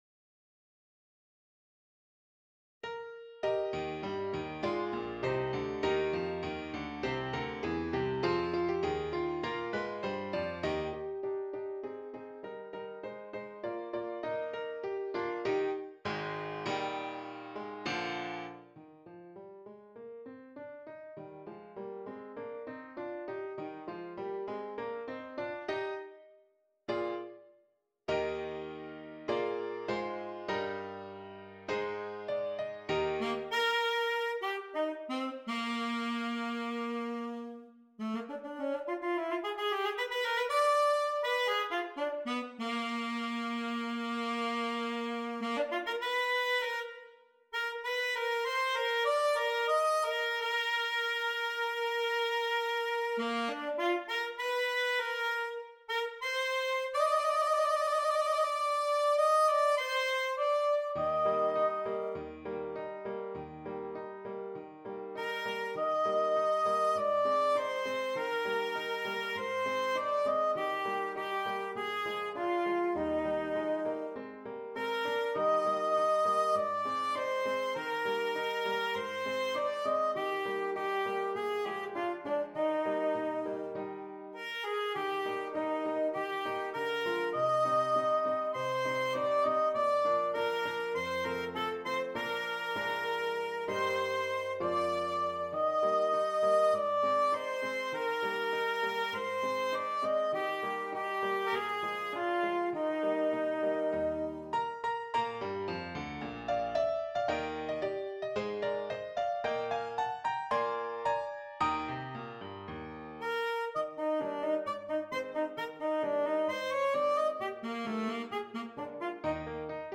Alto Saxophone and Keyboard
Traditional